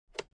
دانلود آهنگ کلیک 35 از افکت صوتی اشیاء
دانلود صدای کلیک 35 از ساعد نیوز با لینک مستقیم و کیفیت بالا
جلوه های صوتی